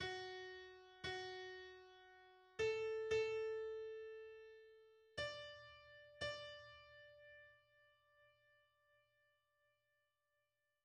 In Paradisum S Re maggiore 3/4 Andante moderato